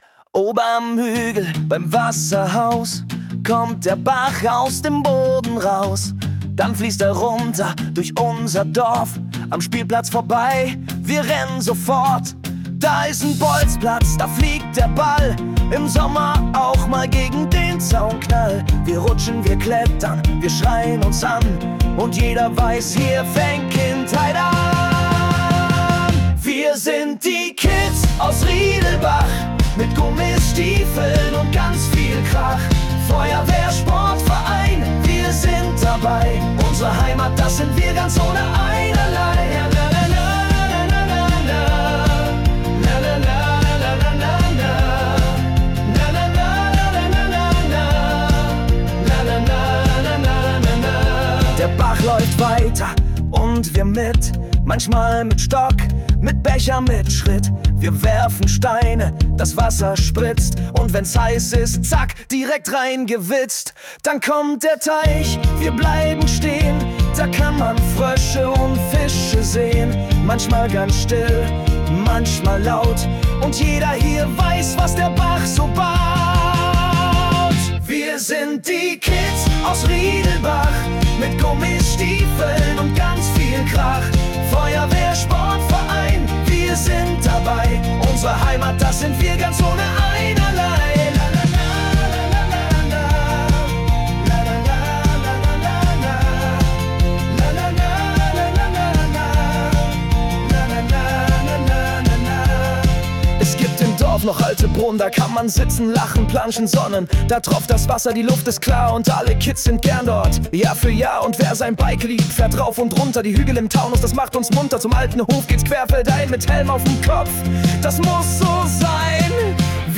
Kinderlied Riedelbach.mp3